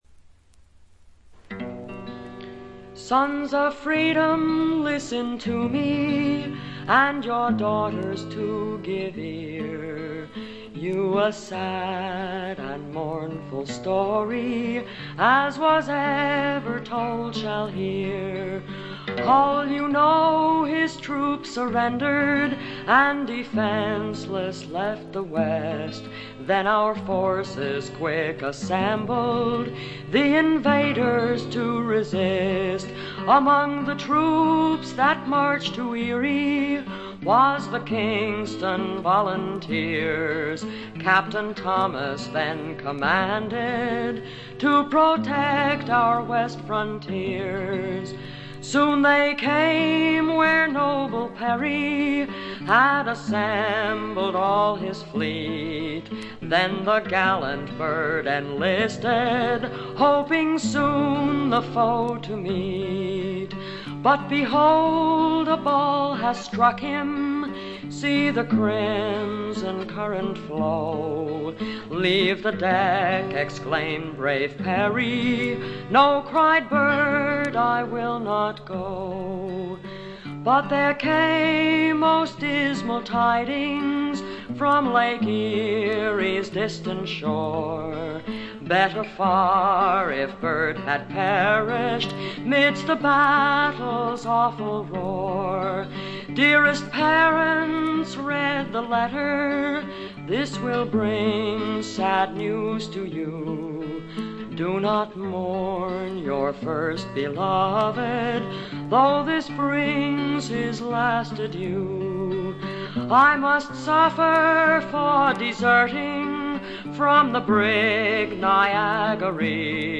軽微なバックグラウンドノイズにチリプチ少し。
魅力的なヴォイスでしっとりと情感豊かに歌います。
試聴曲は現品からの取り込み音源です。
Recorded At - WDUQ, Pittsburgh, PA